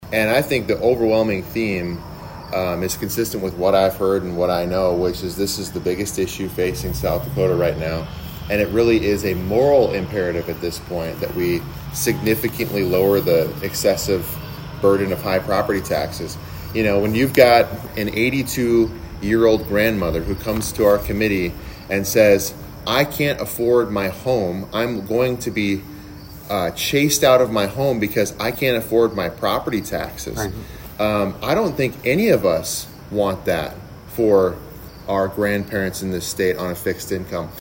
HubCityRadio had an opportunity to do an interview with him to address several different topics.